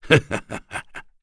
Bernheim-Vox_Happy2.wav